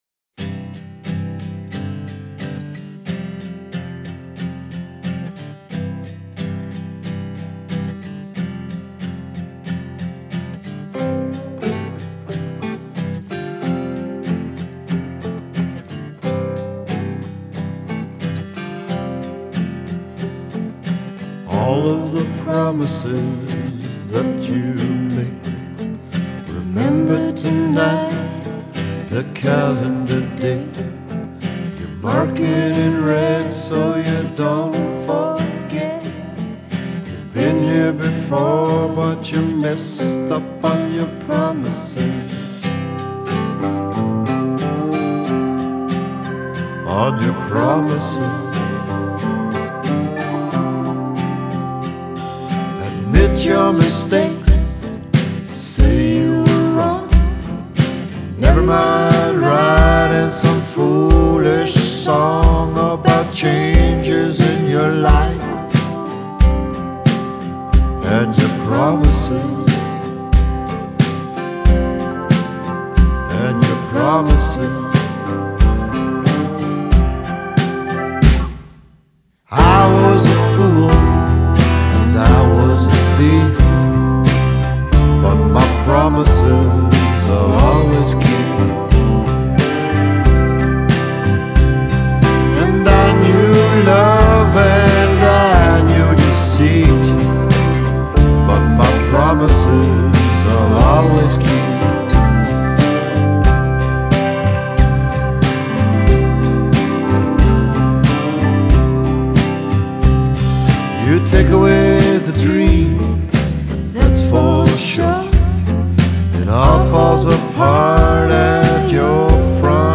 soulful
is a slow burn that is both musical and poetic